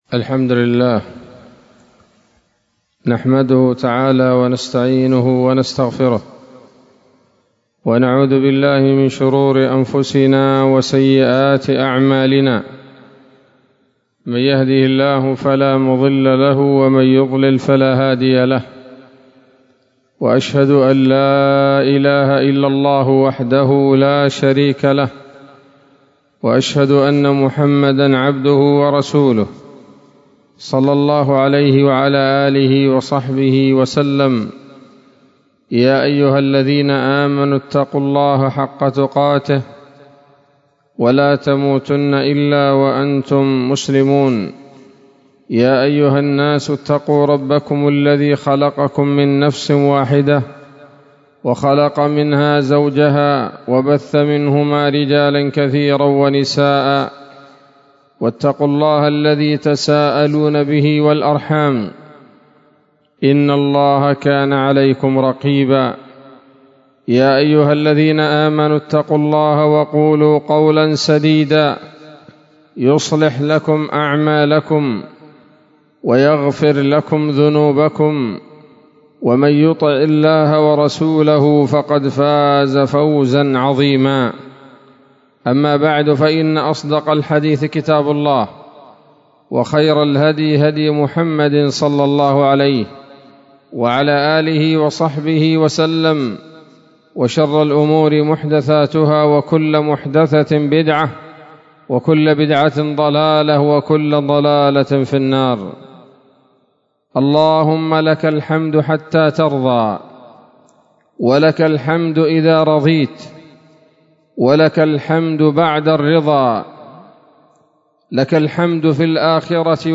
خطبة جمعة بعنوان: (( الحمد وفضائله )) 5 شوال 1443 هـ، دار الحديث السلفية بصلاح الدين